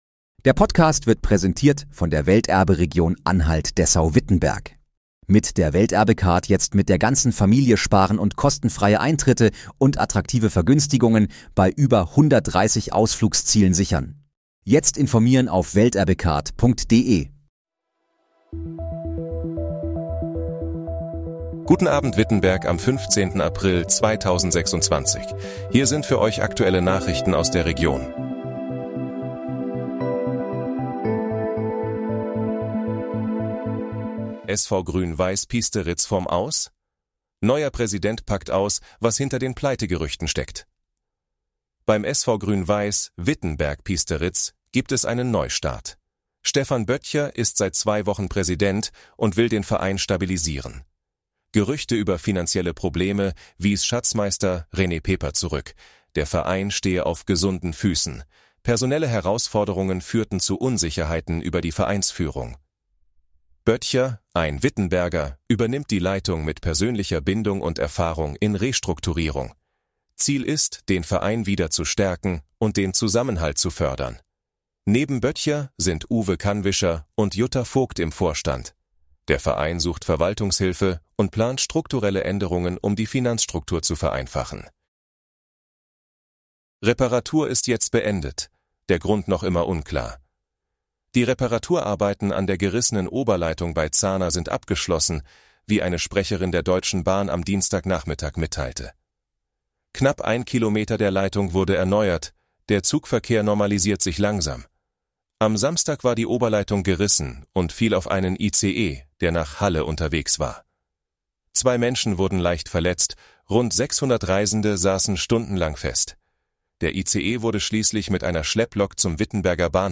Guten Abend, Wittenberg: Aktuelle Nachrichten vom 15.04.2026, erstellt mit KI-Unterstützung